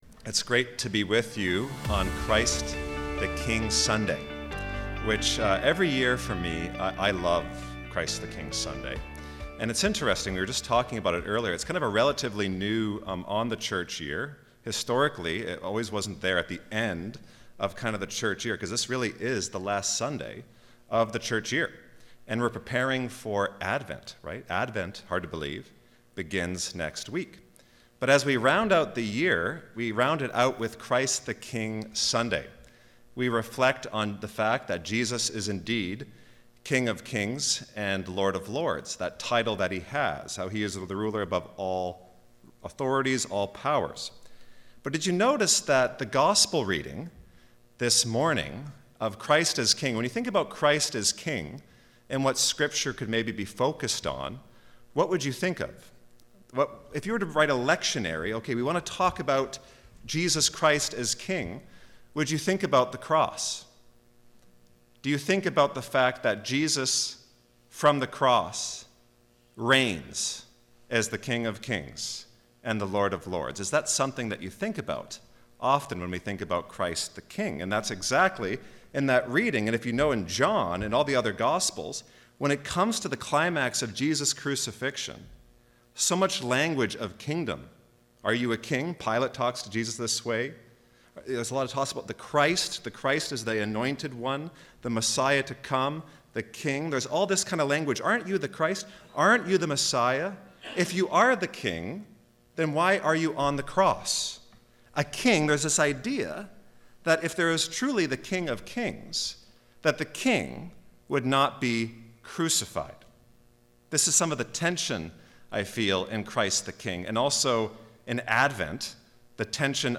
Sermons | Church of the Ascension